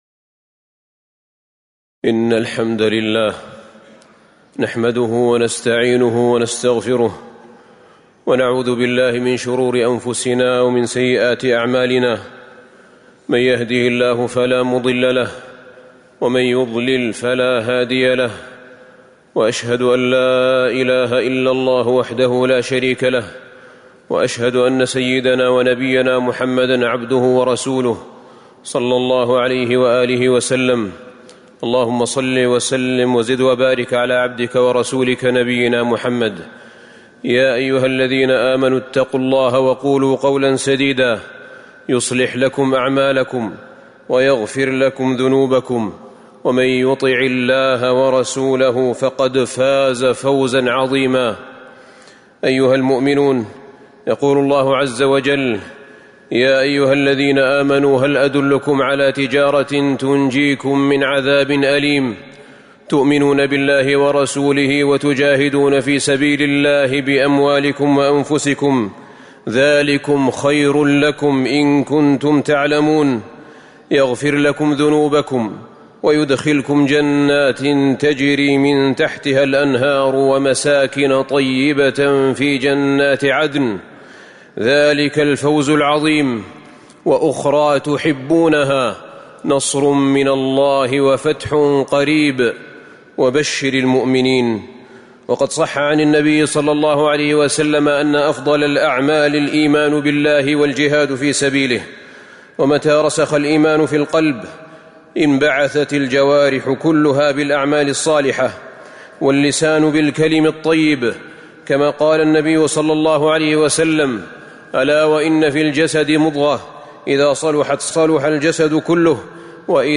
خطبة جهاد القاصد وسلوان القاعد وفيها: عظم منزلة الجهاد، وقصد بيوت الله من أعظم أنواع الجهاد، وعظم جزاء الحج
تاريخ النشر ٢ ذو الحجة ١٤٤٣ المكان: المسجد النبوي الشيخ: فضيلة الشيخ أحمد بن طالب حميد فضيلة الشيخ أحمد بن طالب حميد جهاد القاصد وسلوان القاعد